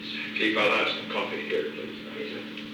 Secret White House Tapes
Conversation No. 422-19
Location: Executive Office Building